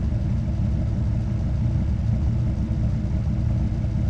rr3-assets/files/.depot/audio/Vehicles/v10_02/v10_02_idle.wav
v10_02_idle.wav